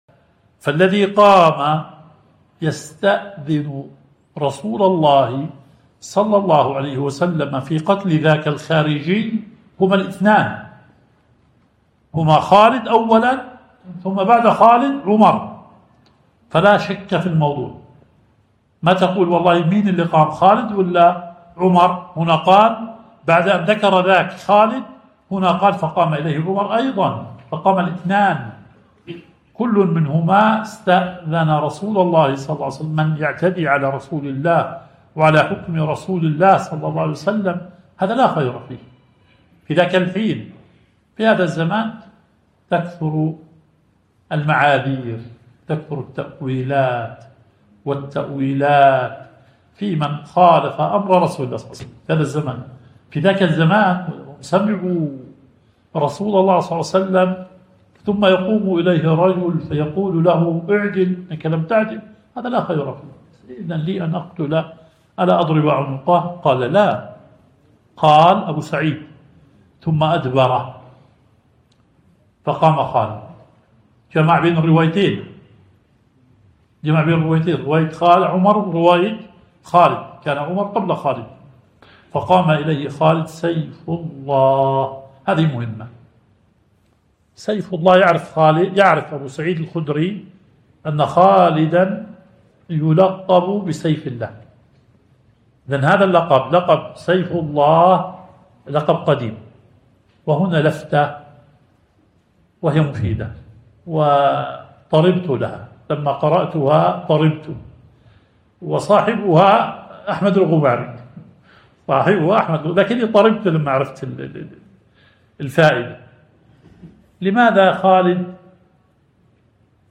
درس شرح صحيح مسلم